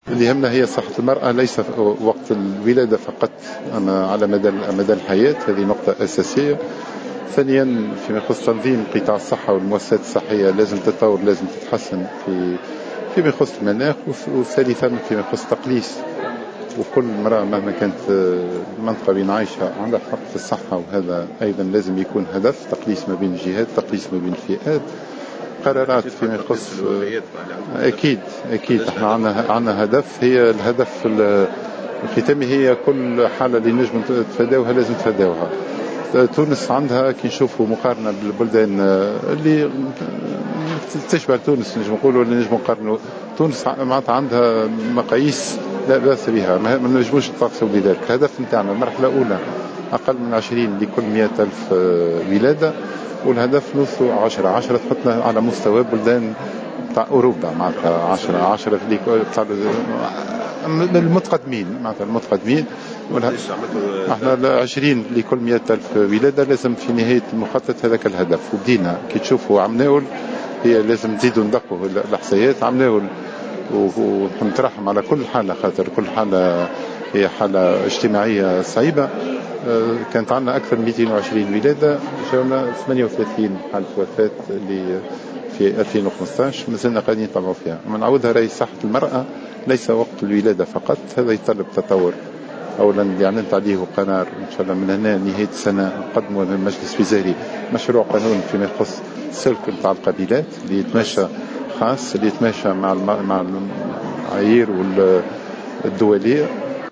أكد وزير الصحة سعيد العايدي في تصريح لمراسلة "الجوهرة أف أم" خلال إشرافه اليوم على الاحتفال باليوم العالمي للقابلات في الحمامات أنه تم خلال سنة 2015 تسجيل 38 حالة وفاة لنساء أثناء الولادة.